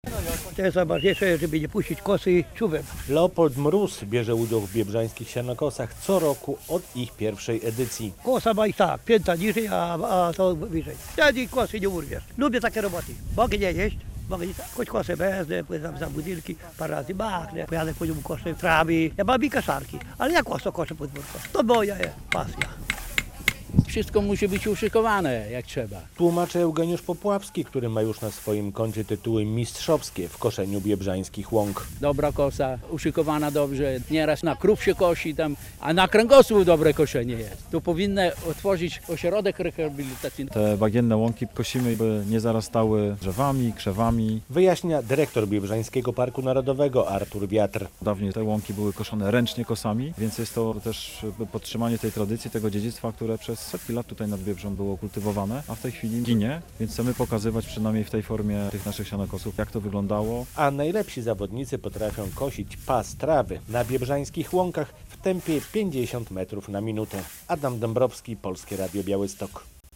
Biebrzański Park Narodowy po raz dziewiętnasty zorganizował Biebrzańskie Sianokosy - relacja